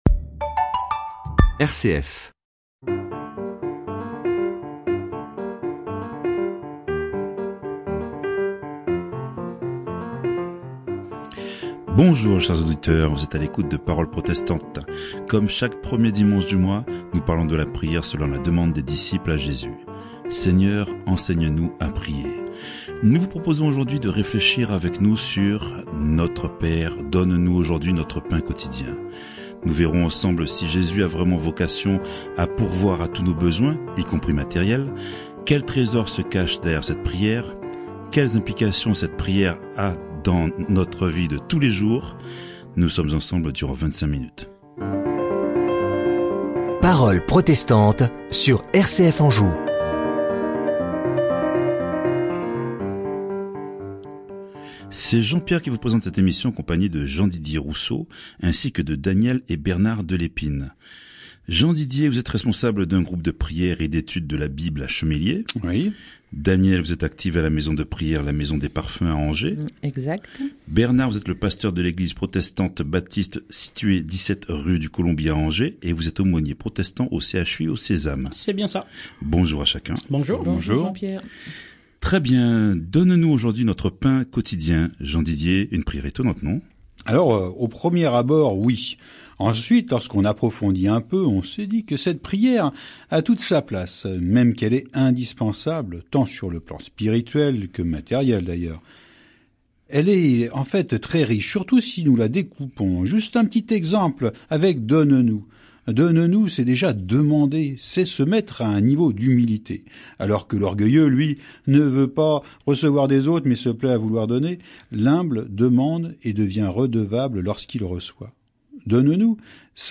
Emission de radio RCF - Parole Protestante